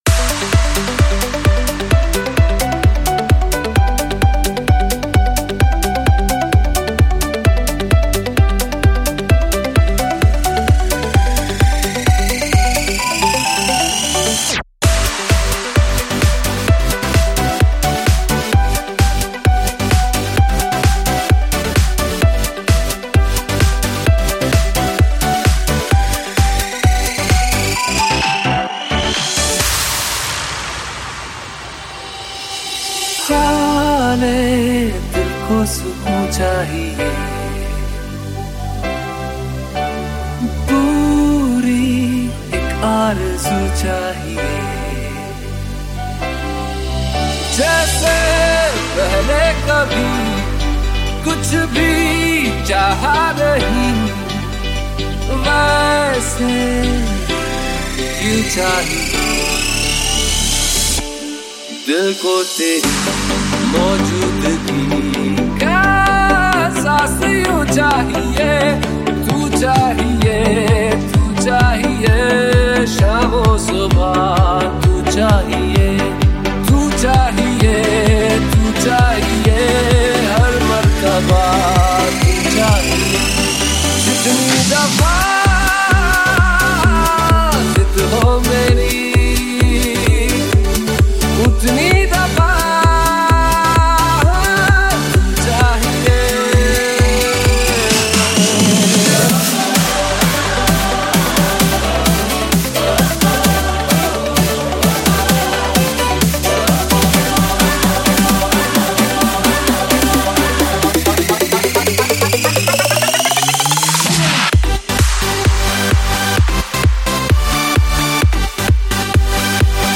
Single Dj Mixes